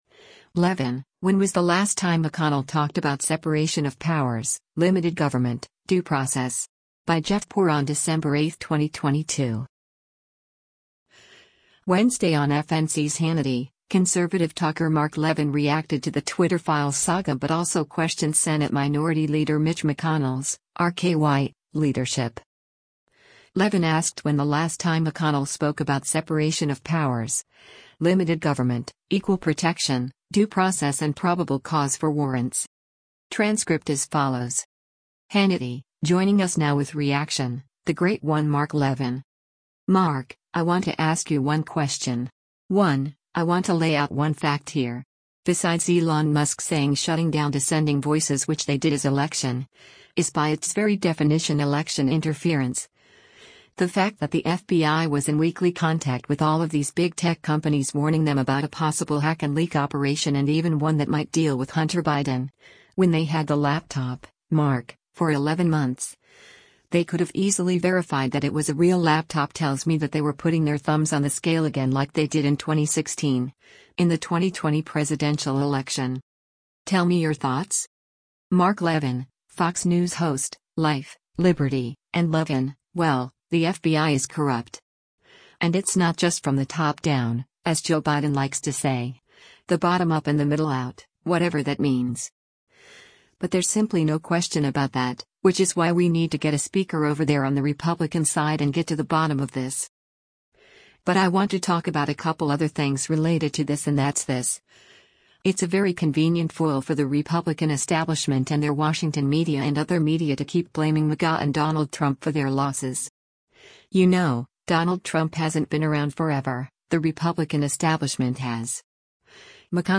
Wednesday on FNC’s “Hannity,” conservative talker Mark Levin reacted to the “Twitter files” saga but also questioned Senate Minority Leader Mitch McConnell’s (R-KY) leadership.